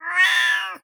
SFX_Cat_Meow_03.wav